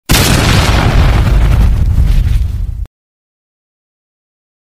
Sound Effect Bunyi Ledakan
Kategori: Suara meme
sound-effect-bunyi-ledakan-id-www_tiengdong_com.mp3